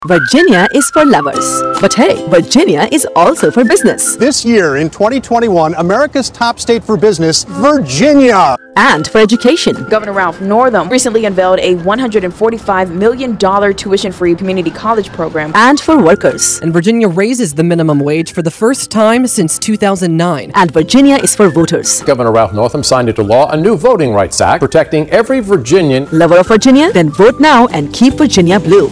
She can modulate her voice to be serious, cheeky, matter-of-fact, motivational, exhorting or whatever else the content demands.
A LITTLE SECRET - Did you know that using a TransAtlantic voice (not too American, not too British, but a bit of both) perks up the ears of the listener helping your message stand out from the crowd?